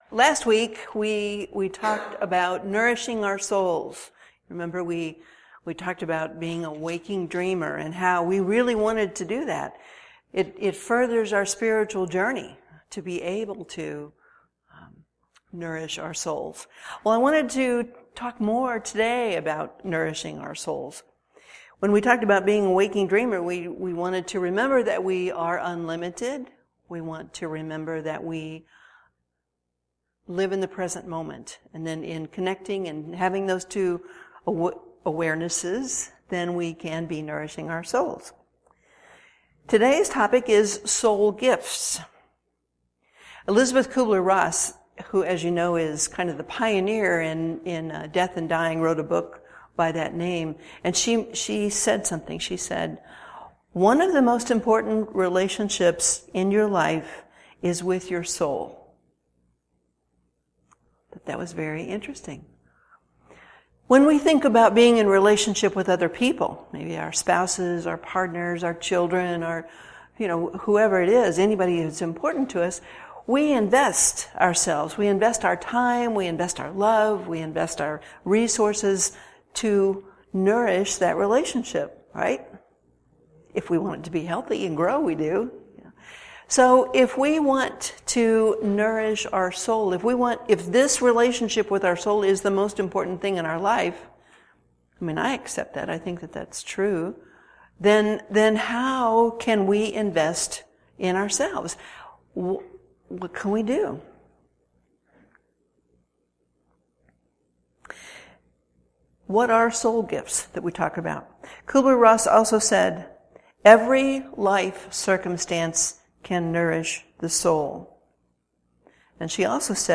Sermon Mp3s